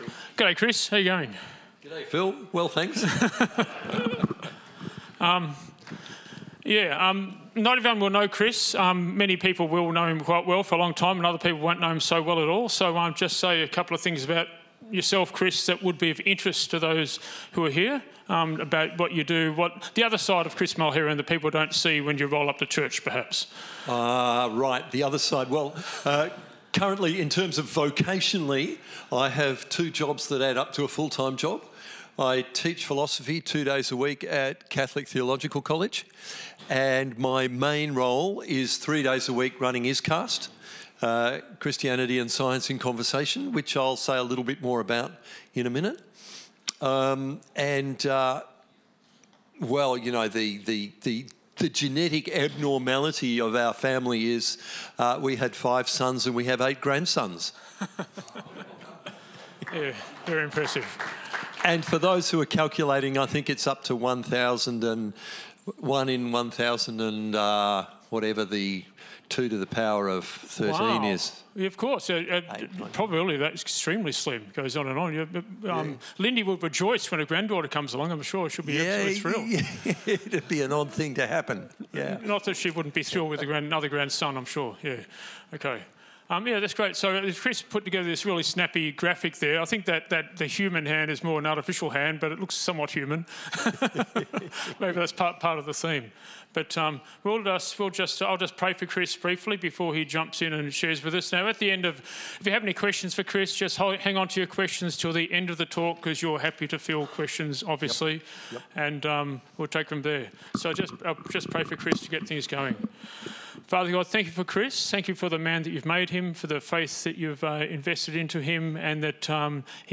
[post_id=31351] Men's Event Current Sermon Men's Event - Servant or Saviour?